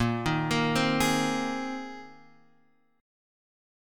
Bb9sus4 chord